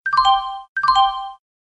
Message Tones sms , cute , bell , announce , railway